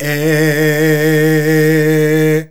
AEAEAEH A#.wav